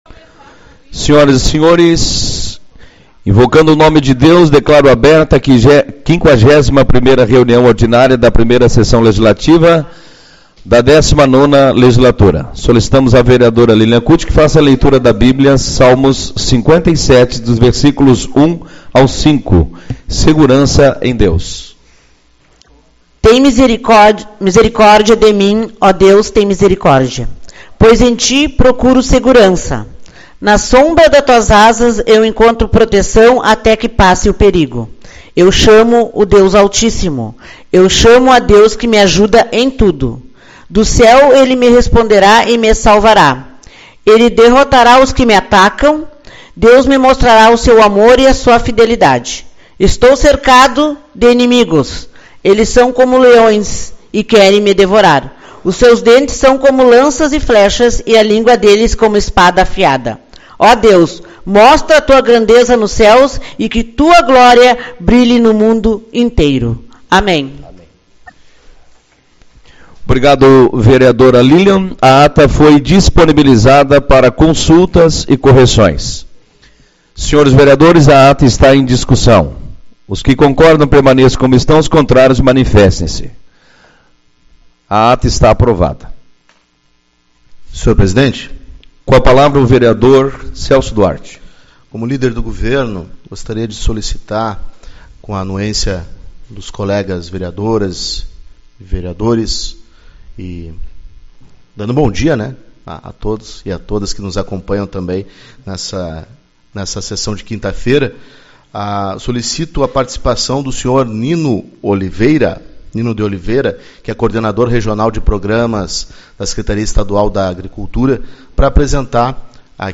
21/08 - Reunião Ordinária